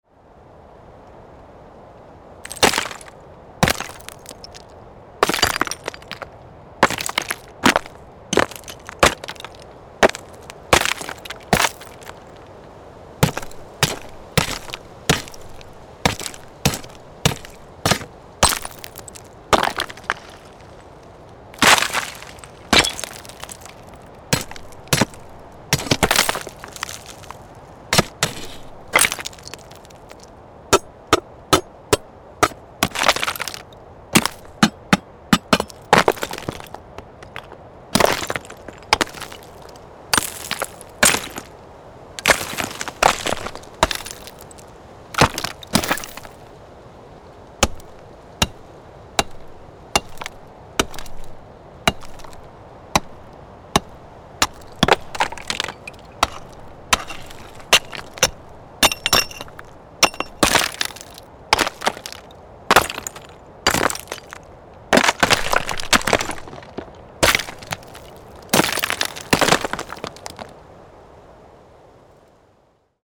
Stone Impact Sound Effects
This pack delivers ready-to-use hits, fractures, and falling debris sounds for your projects.